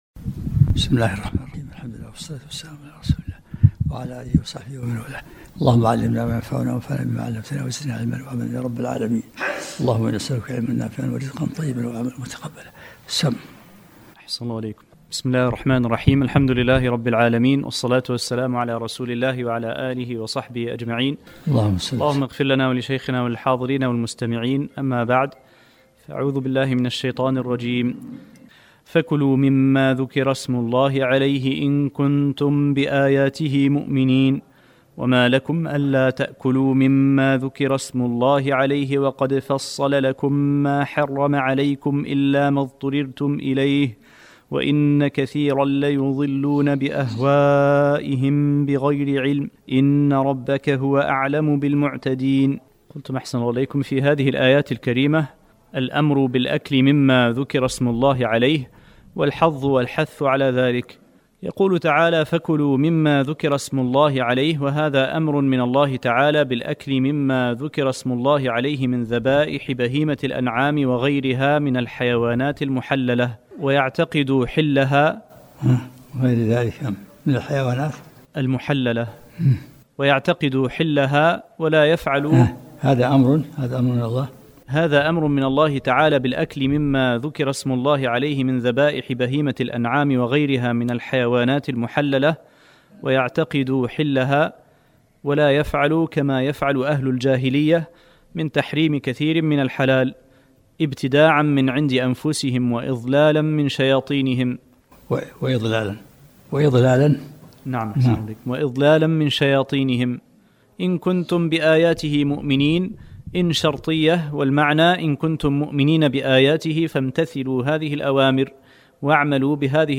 الدرس الرابع و العشرون من سورة الانعام